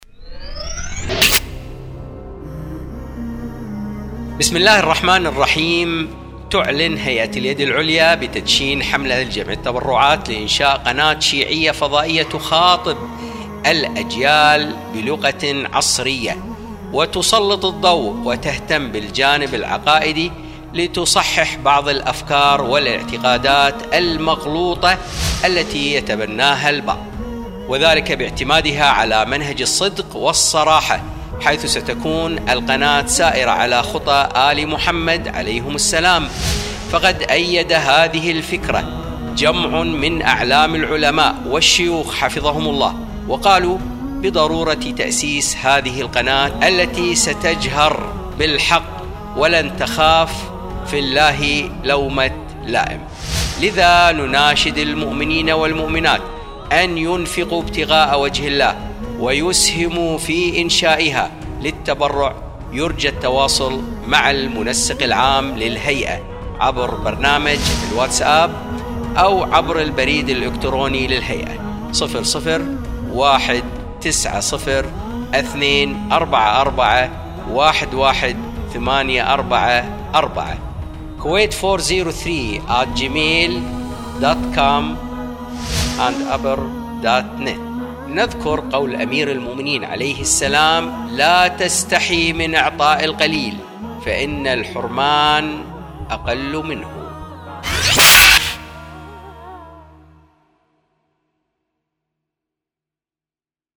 مكان التسجيل: الكويت